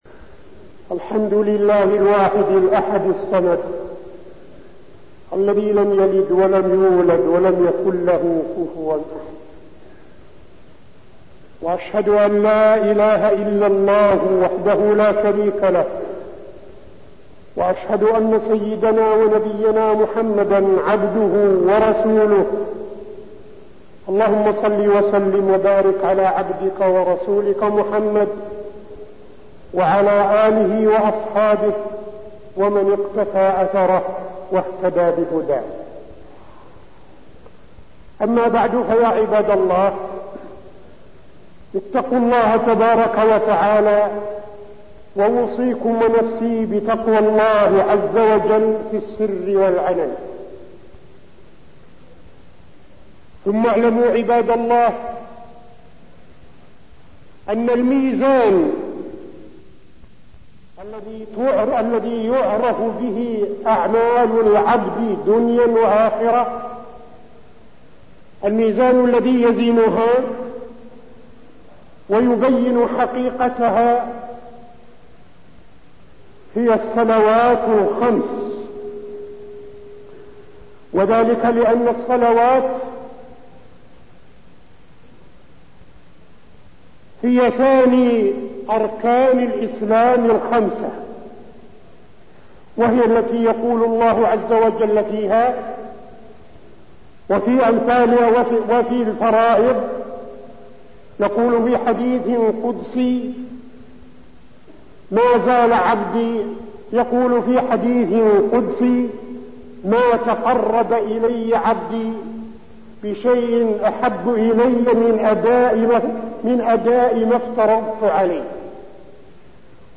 تاريخ النشر ١٧ جمادى الآخرة ١٤٠٥ هـ المكان: المسجد النبوي الشيخ: فضيلة الشيخ عبدالعزيز بن صالح فضيلة الشيخ عبدالعزيز بن صالح الصلاة وحكم تاركها